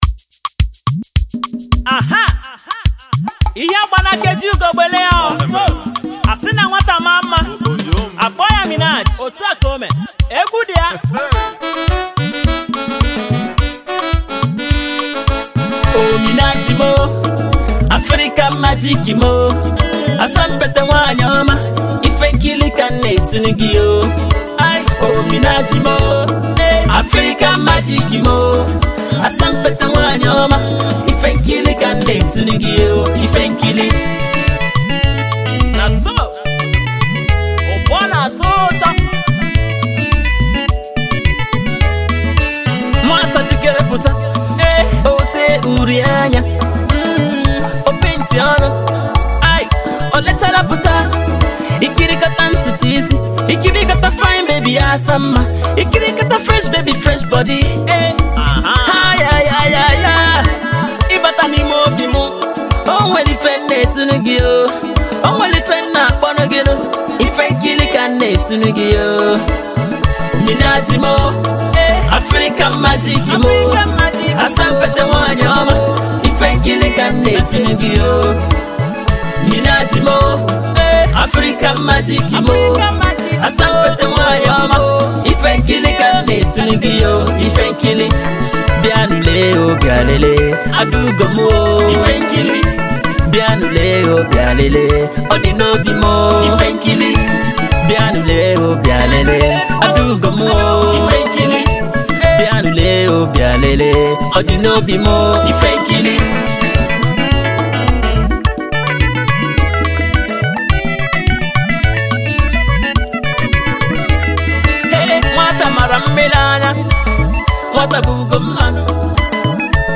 His melody and harmony is straight to the soul.